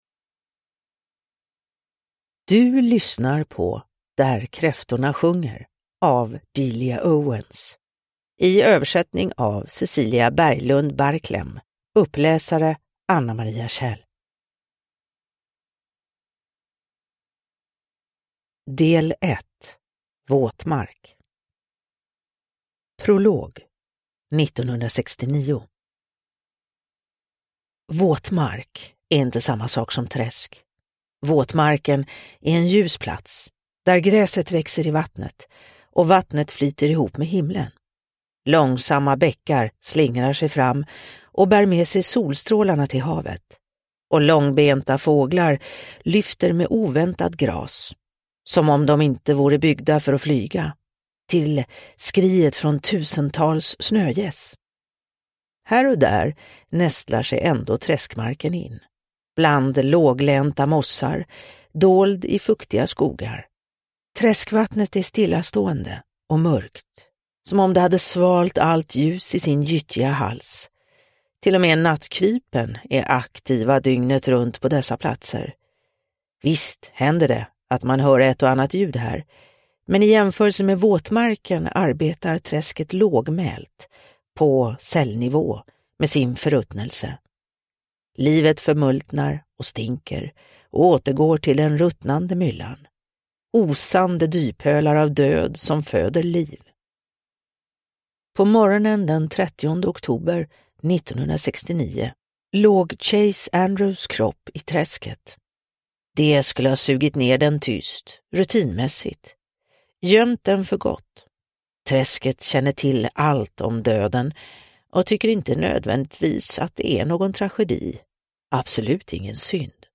Där kräftorna sjunger – Ljudbok – Laddas ner